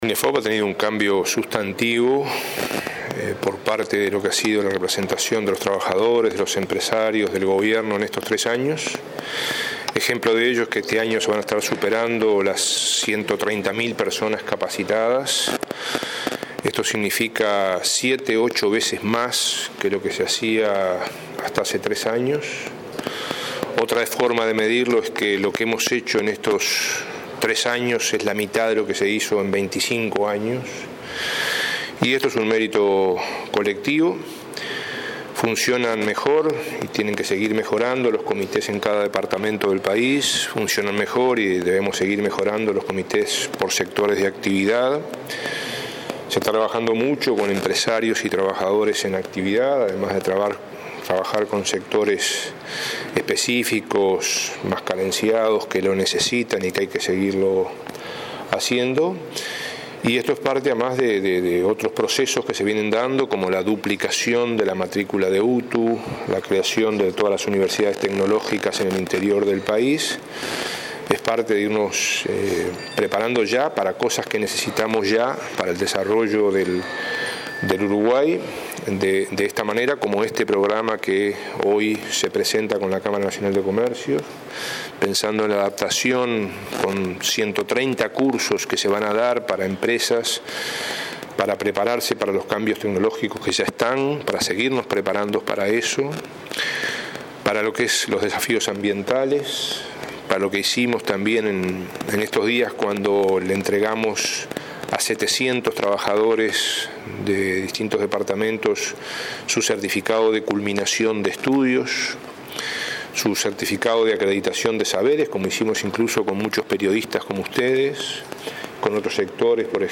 El ministro Ernesto Murro destacó el cambio sustantivo que ha tenido Inefop que superará este año las 130 mil personas capacitadas, 8 veces más que hace tres años. “Se está trabajando con empresarios y trabajadores y también con sectores carenciados; esto es parte de otros procesos como la duplicación de la matrícula de UTU”, sostuvo durante el lanzamiento del programa de reconversión laboral de Inefop y la Cámara de Comercio.